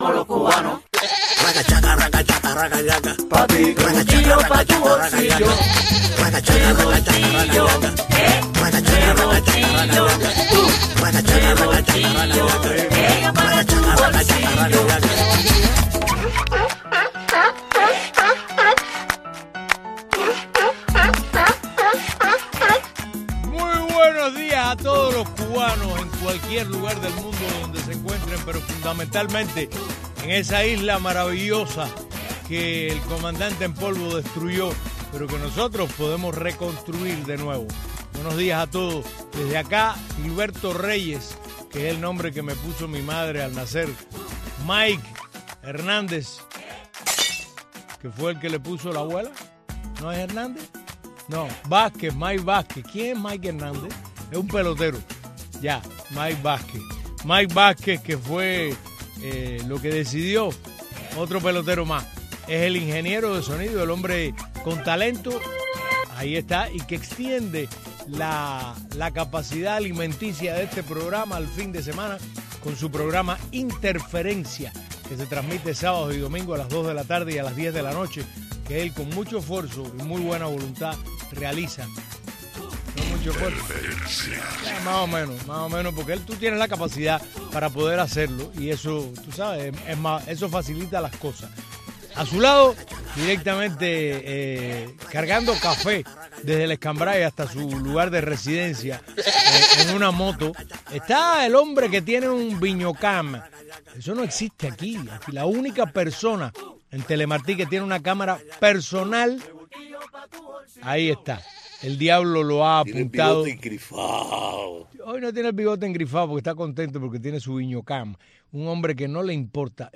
programa matutino